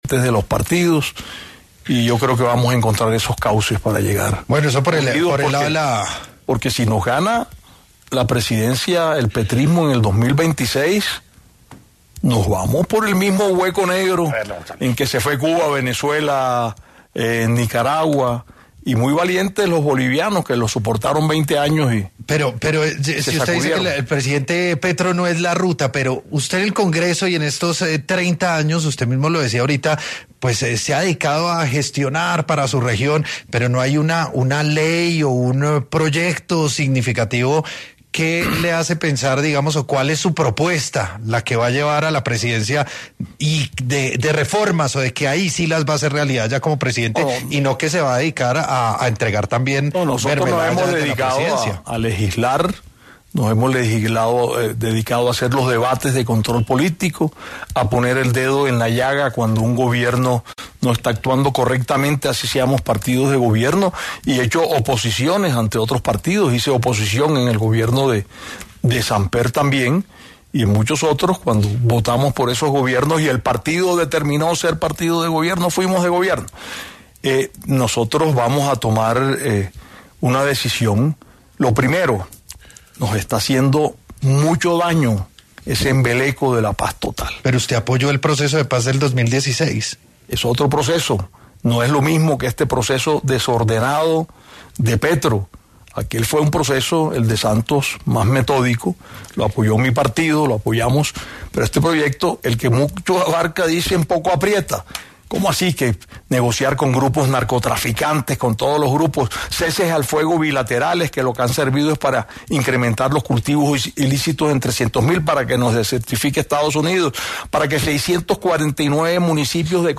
En su paso por Sin Anestesia, un programa de Caracol Radio, estuvo Efraín Cepeda, quien habló sobre las diferencias entre el proceso de paz del 2016 con el actual, y lo que él haría bajo su gobierno si gana